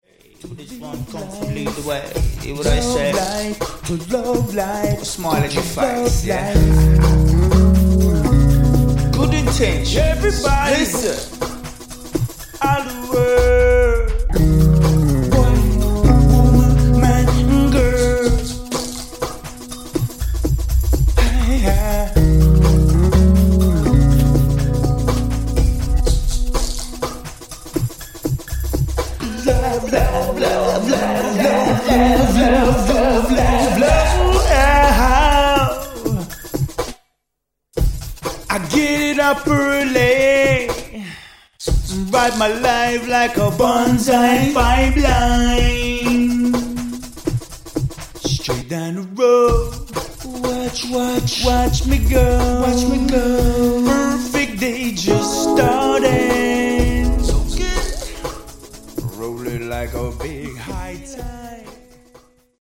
D&B - Jazz